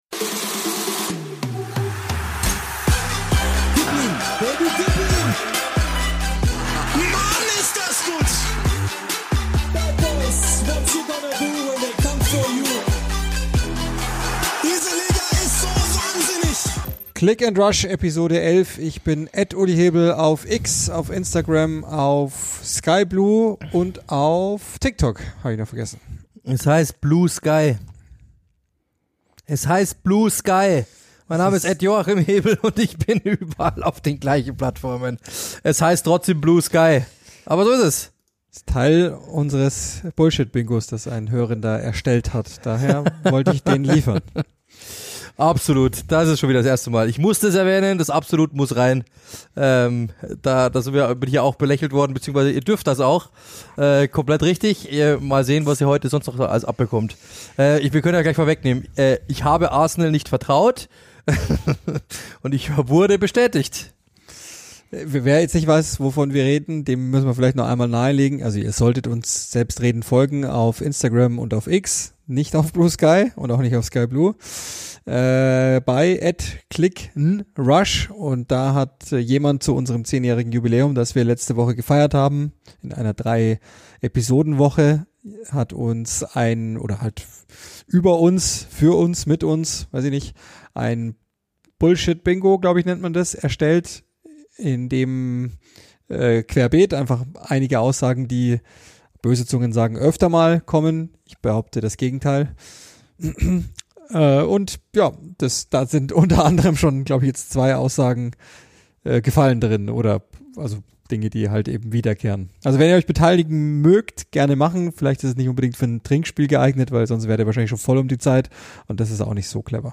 1 Matussek!: Im Gespräch mit Tino Chrupalla 50:17